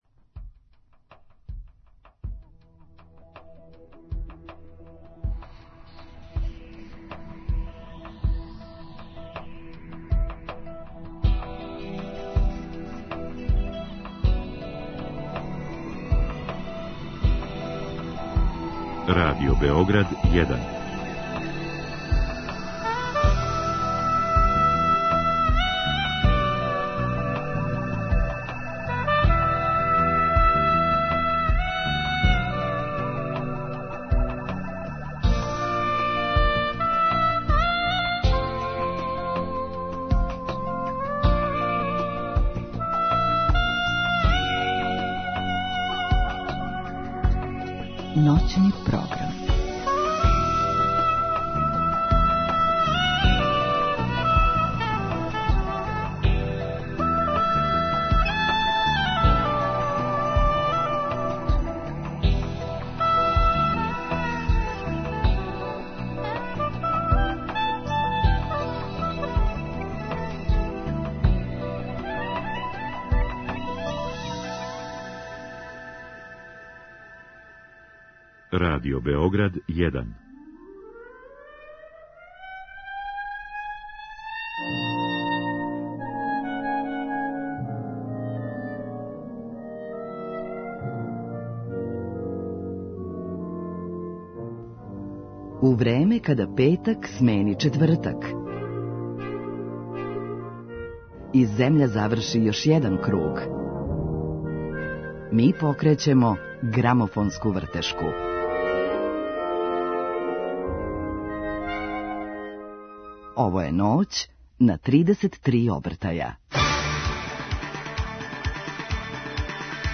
Други сат је резервисан на хитове са Билбордове листе у години из прошлости, а дружење завршавамо новитетом на винилу.